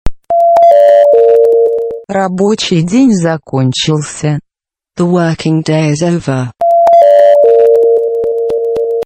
Категория: Напоминание